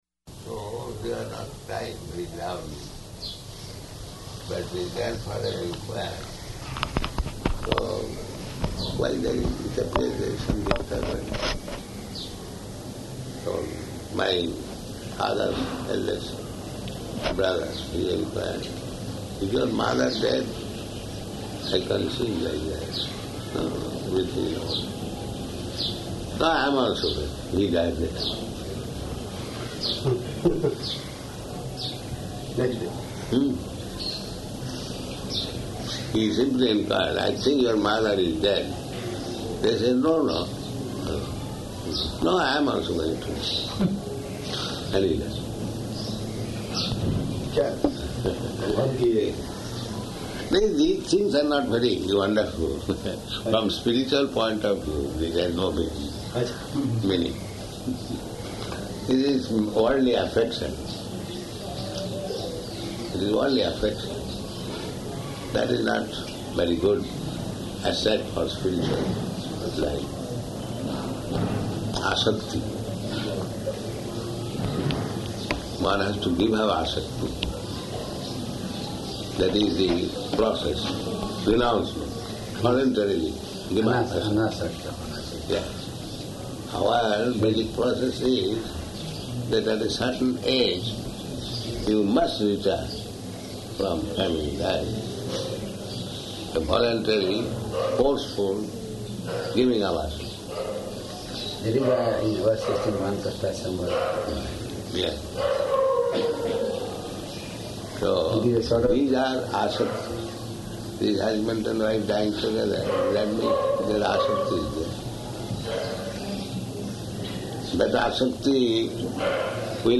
Room Conversation
Type: Conversation
Location: Bombay